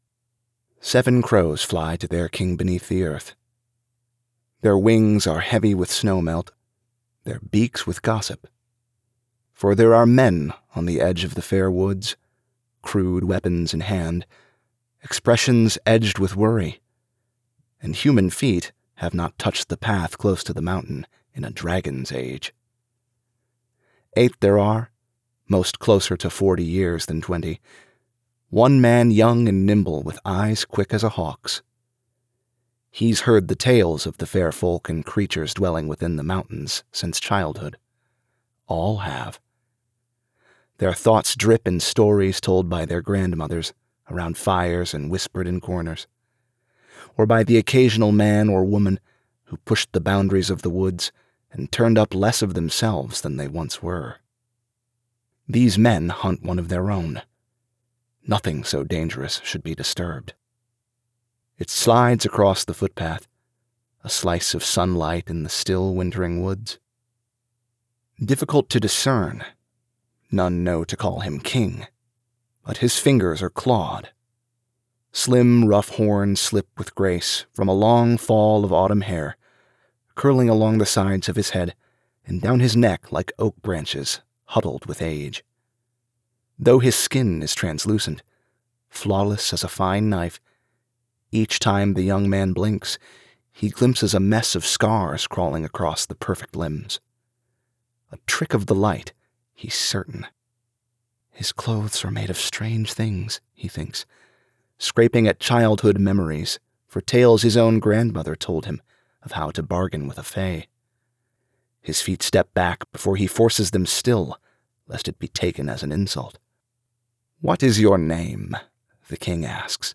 Narrator
Audiobook 3 - Under the Earth Over the Sky - High Fantasy (1).mp3